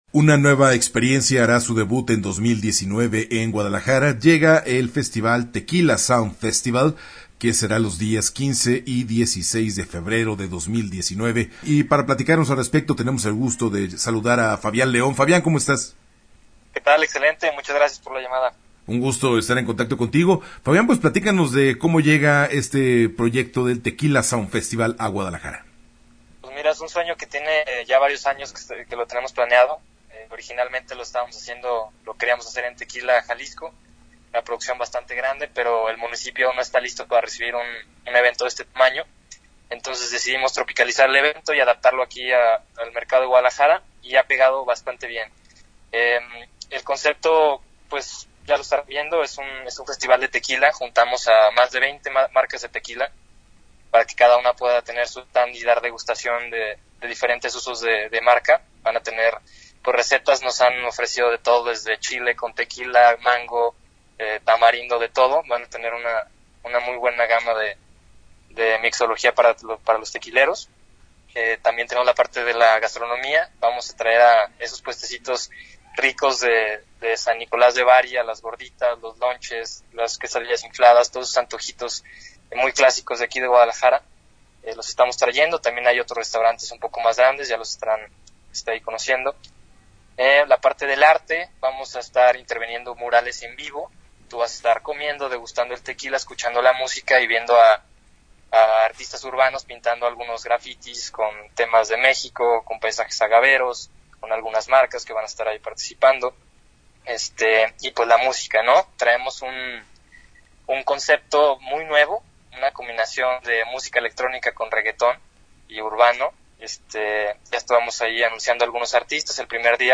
Entrevista-Tequila-Sound-Festival-2019-web.mp3